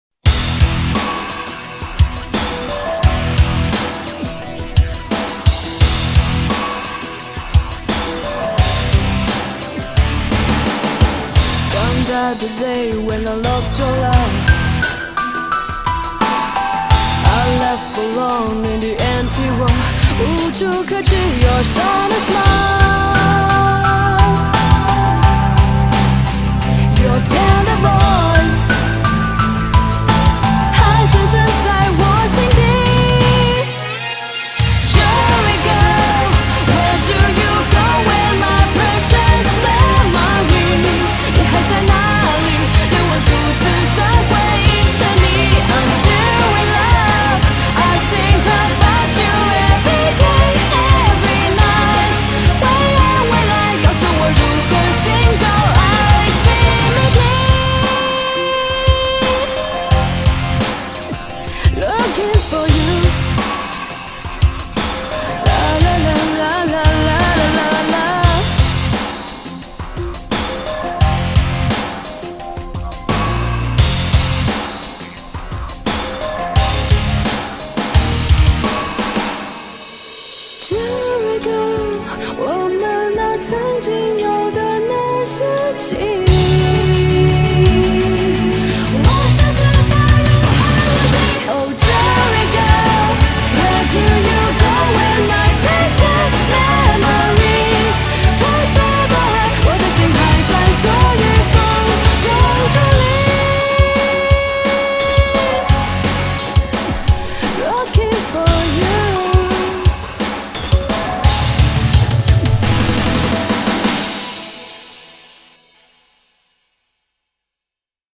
BPM173
Audio QualityPerfect (High Quality)
rock n' roll song